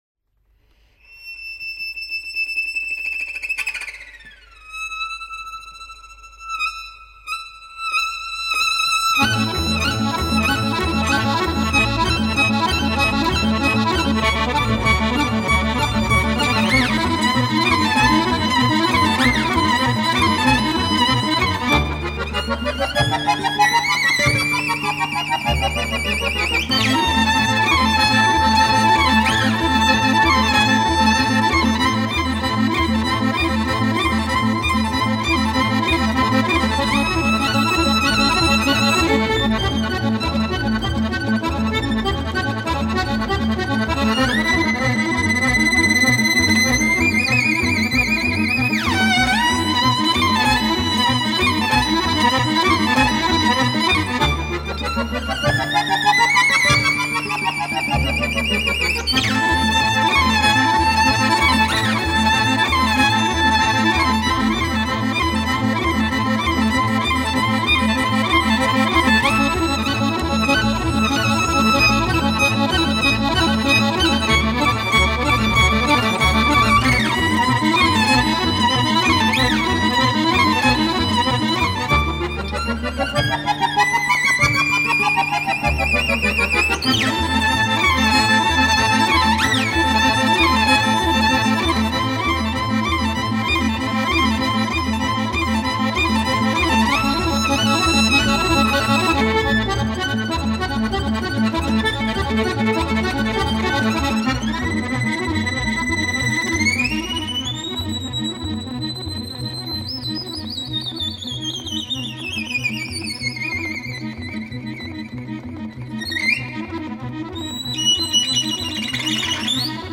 violin
accordion
guitar
double bass.